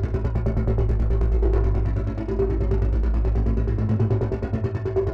Index of /musicradar/dystopian-drone-samples/Tempo Loops/140bpm
DD_TempoDroneE_140-A.wav